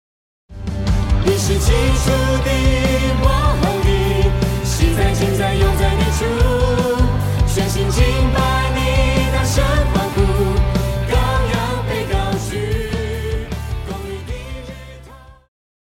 宗教
流行-合唱
樂團
流行音樂,教會音樂
歌唱曲
聲樂與伴奏
有主奏
有節拍器